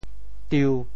How to say the words 丢 in Teochew？
丢 Radical and Phonetic Radical 丿 Total Number of Strokes 6 Number of Strokes 5 Mandarin Reading diū TeoChew Phonetic TeoThew diu1 文 Chinese Definitions 丢 <动> 丢失;遗失 [lose;mislay]。
tiu1.mp3